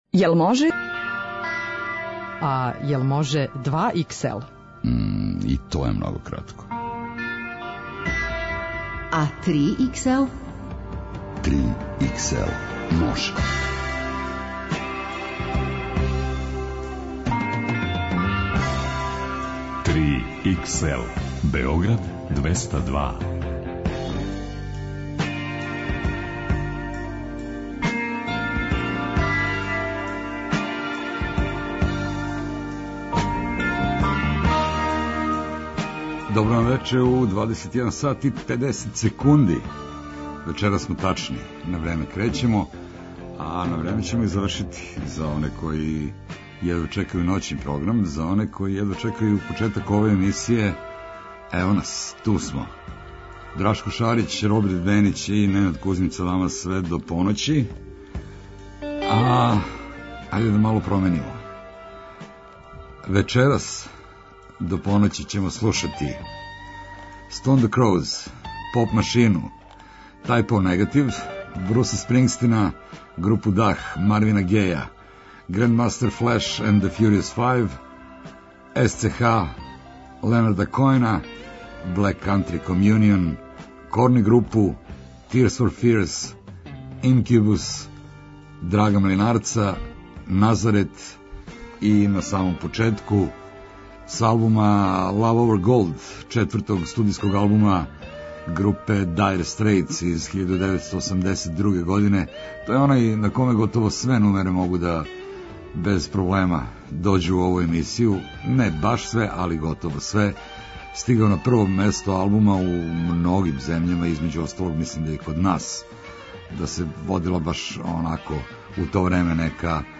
Најдуже музичке нумере.